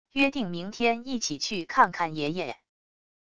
约定明天一起去看看爷爷wav音频生成系统WAV Audio Player